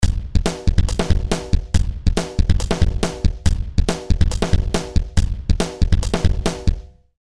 The following are mp3 samples that showcase different settings of Danstortion.
Drums
Drums_neg_100.mp3